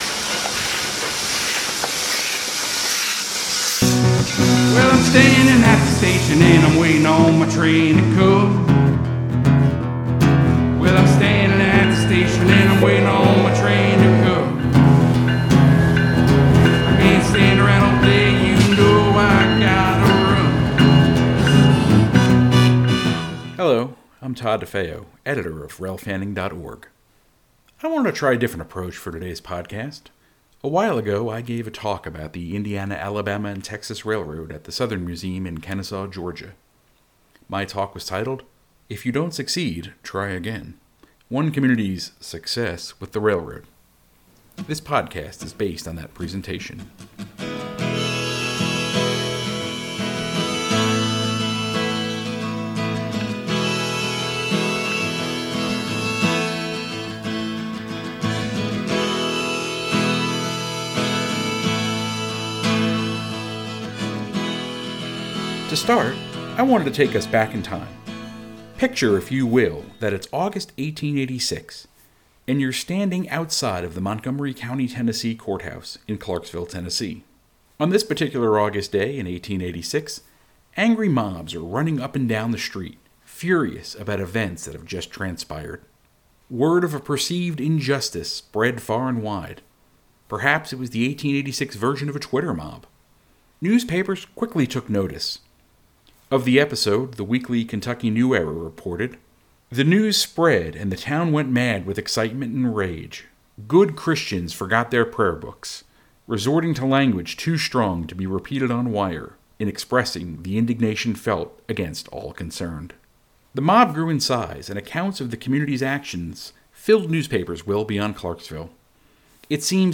Sound Effects Diesel Horn: Recorded at the Southeastern Railway Museum on November 14, 2020. Steam Train: 1880s Train, recorded September 12, 2020, in Hill City, South Dakota.